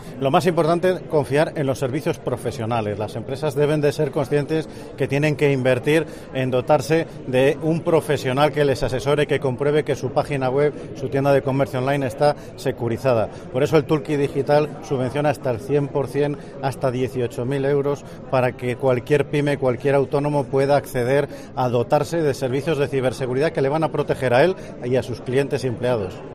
El director Instituto Nacional de Ciberseguridad de España INCIBE, Félix Barrio, ha ofrecido estos consejos desde Ávila; (ESCUCHAR AUDIO)
AUDIO / El director Instituto Nacional de Ciberseguridad de España INCIBE, Félix Barrio